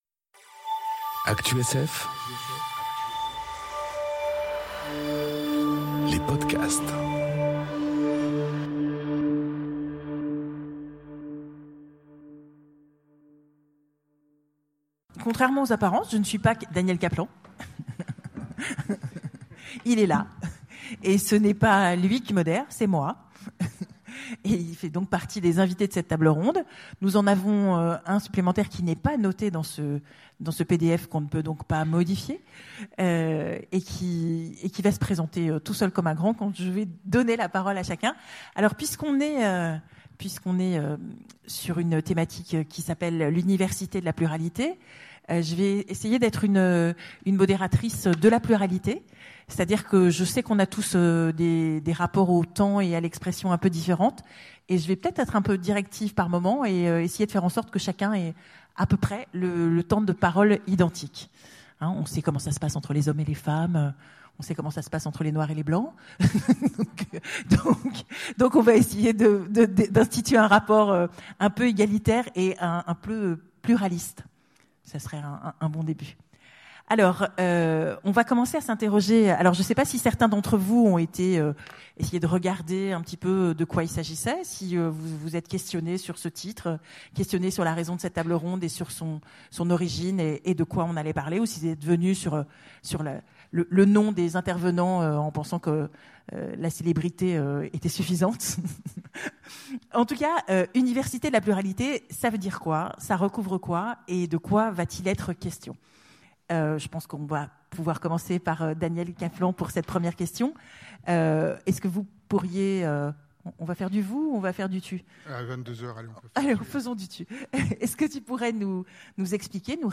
Conférence L'université de la pluralité enregistrée aux Utopiales 2018